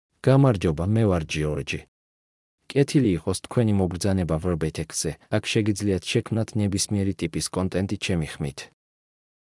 Giorgi — Male Georgian AI voice
Voice sample
Male
Giorgi delivers clear pronunciation with authentic Georgia Georgian intonation, making your content sound professionally produced.